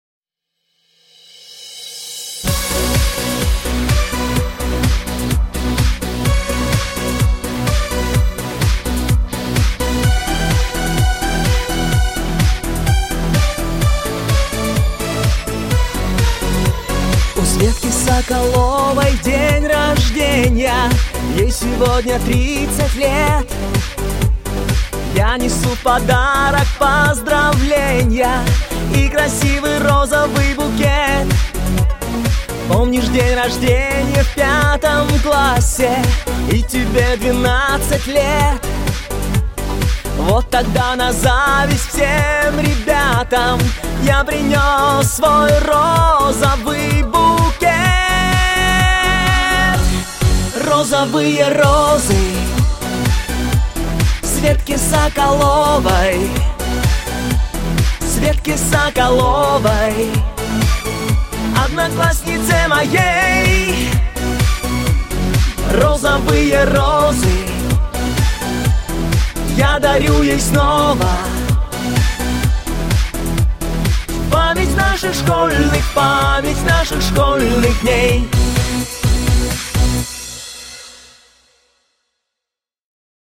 Мужской
Хиты 80-х: